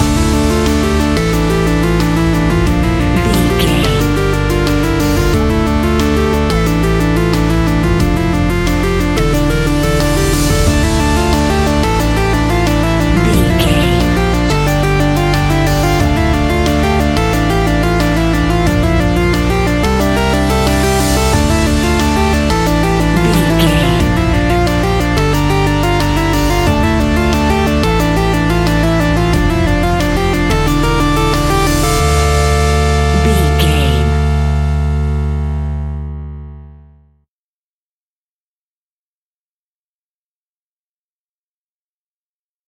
Aeolian/Minor
ominous
dark
eerie
drums
synthesiser
ticking
electronic instrumentals
Horror Synths